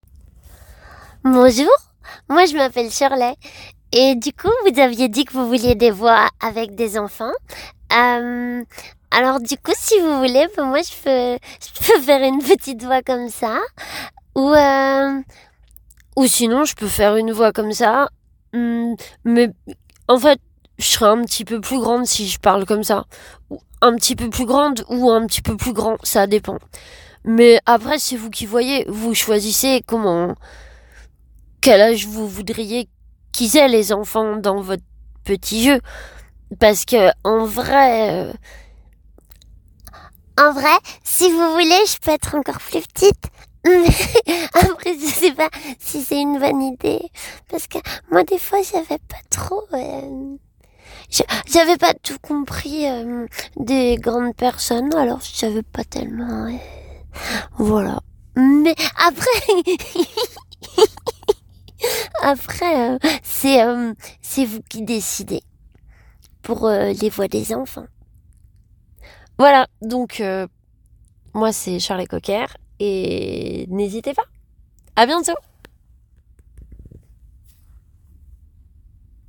Voix off
Voix enfant
- Mezzo-soprano